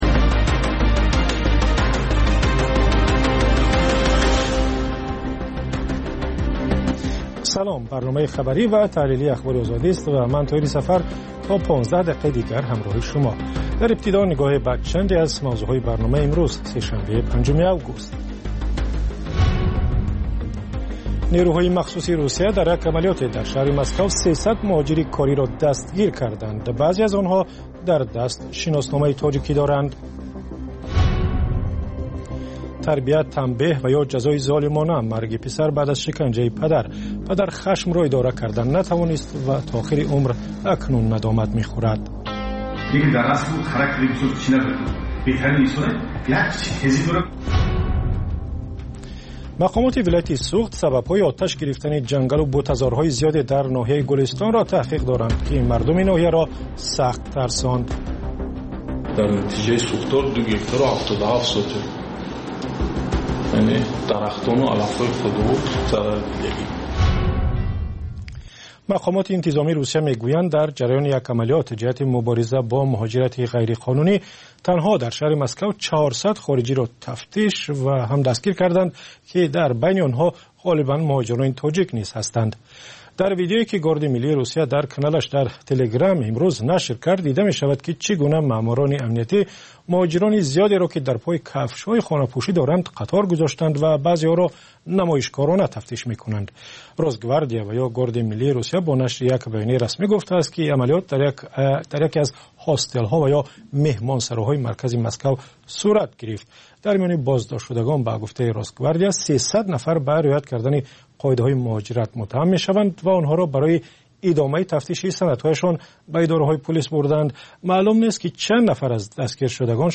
Пахши зинда
Маҷаллаи хабарӣ